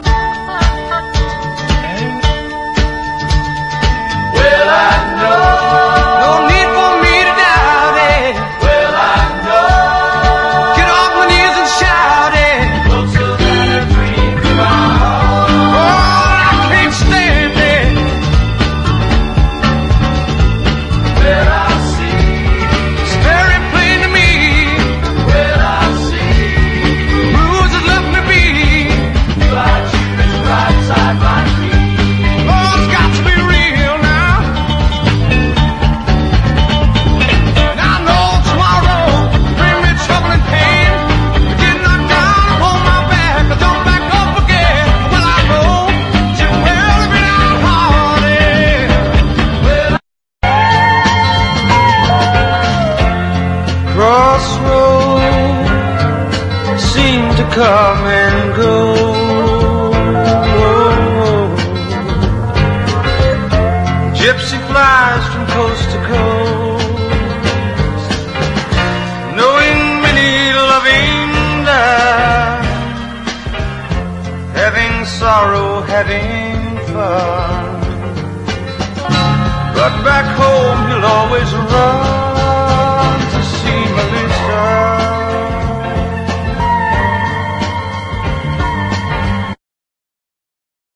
SOUL / SOUL / 70'S～ / DISCO / MDOERN SOUL